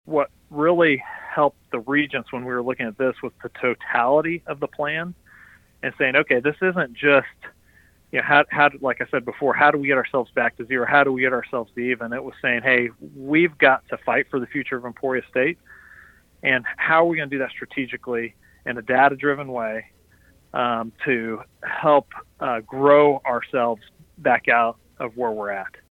In an interview airing Wednesday on KVOE’s Newsmaker segment, Rolph said the reinvestments as part of Emporia State’s Framework for Workforce Management approved in September were designed to help ESU grow out of longstanding budget cuts, a national trend of declining enrollment and — ultimately — a risk of bankruptcy.